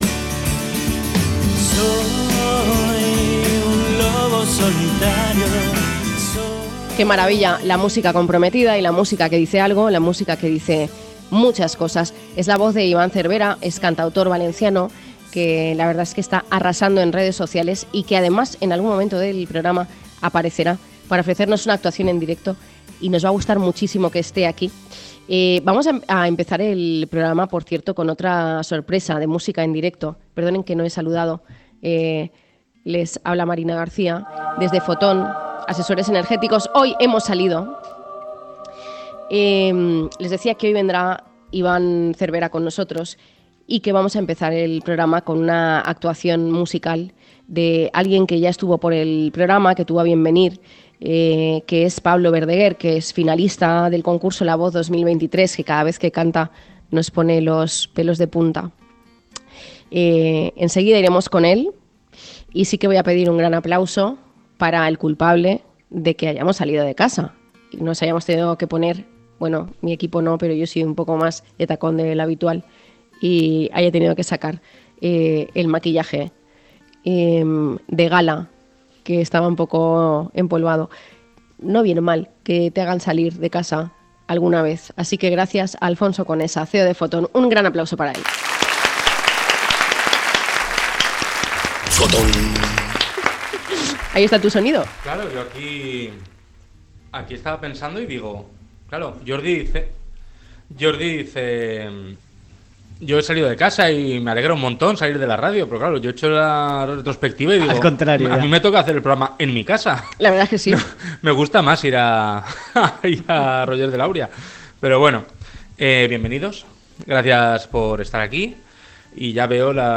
Hablamos desde la sede de Fotón Asesores S.L.